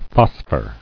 [phos·phor]